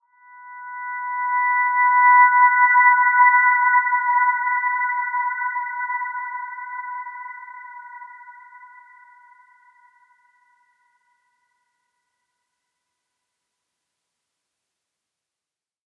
Dreamy-Fifths-B5-p.wav